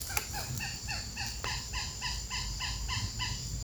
Nombre científico: Astur bicolor guttifer
Nombre en español: Esparvero Variado
Localidad o área protegida: Parque Nacional El Palmar
Condición: Silvestre
Certeza: Fotografiada, Vocalización Grabada